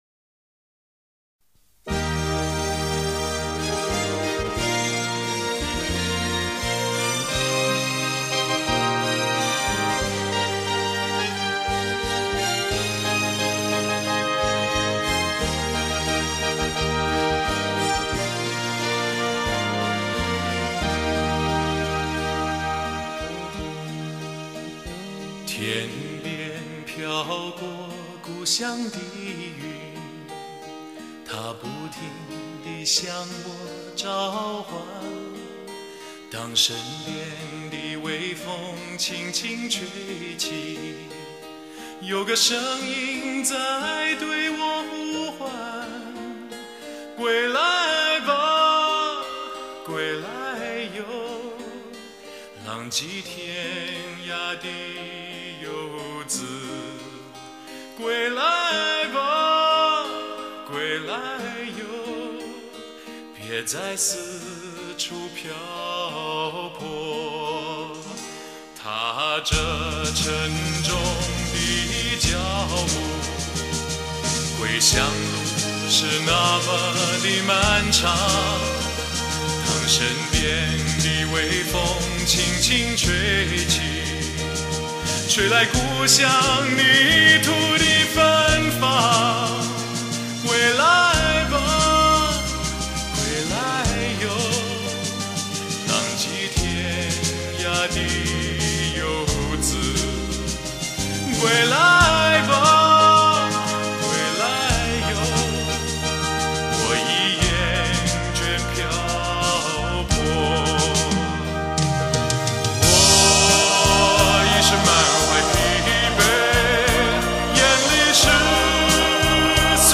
那熟悉的、磁 性十足的嗓音竟然已近在咫尺
那淳厚丰满的质感 绵密而富有弹性